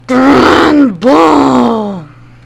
GRANBULL.mp3